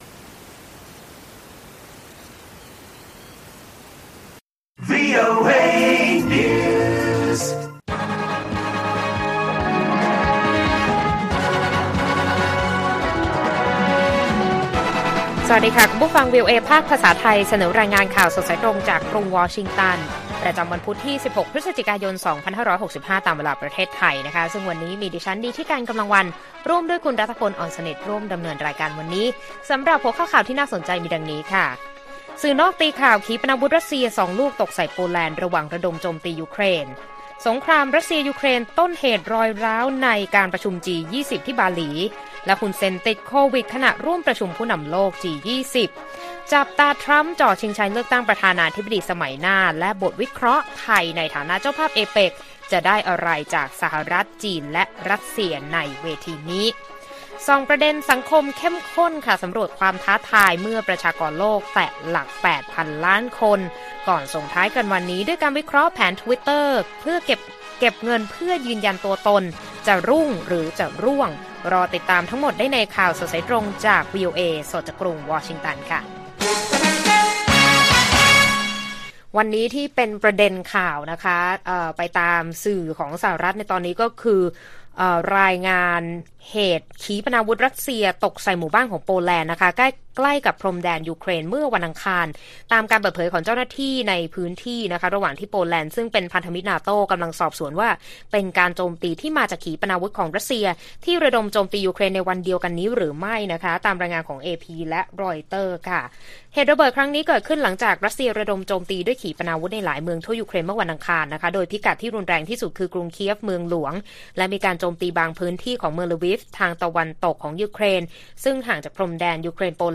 ข่าวสดสายตรงจากวีโอเอ ไทย พุธ 16 พฤศจิกายน 65